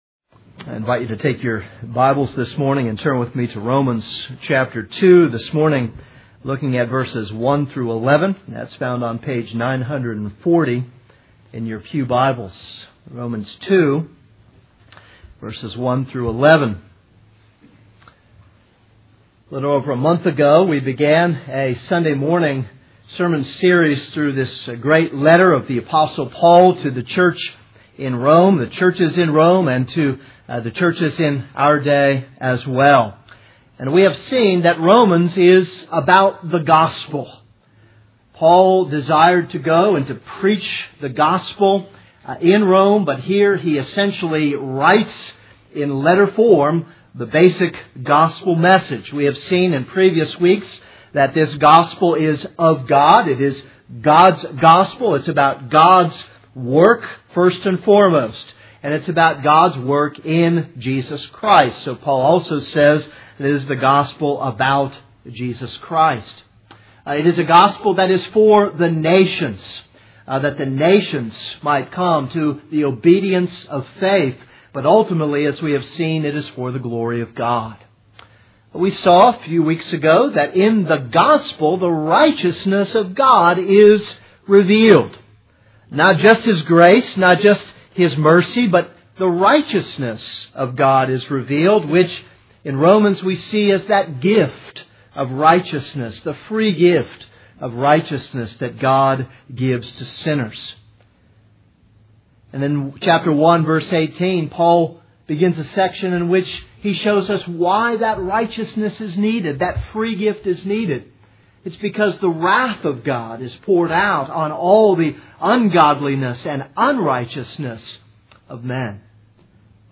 This is a sermon on Romans 2:1-11.